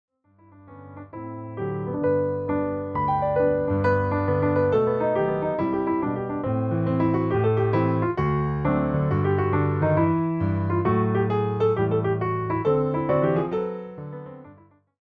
” has a light rhythmic lift.